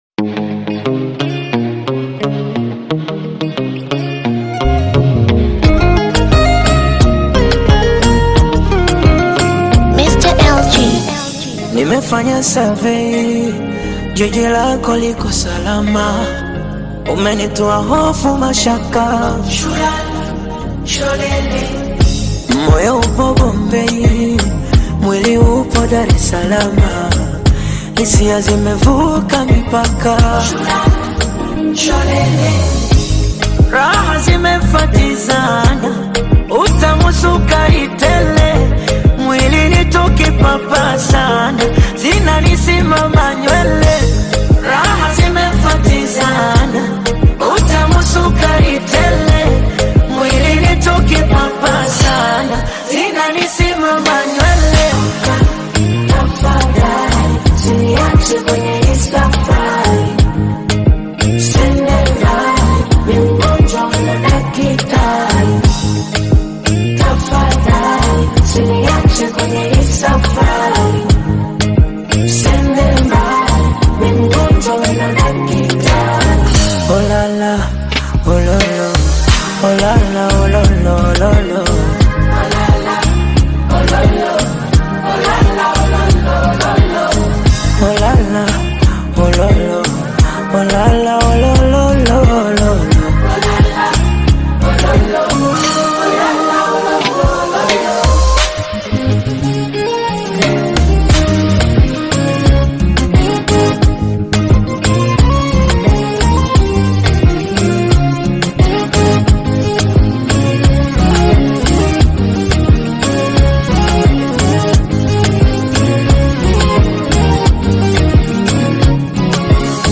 vibrant love song
harmonious and romantic melody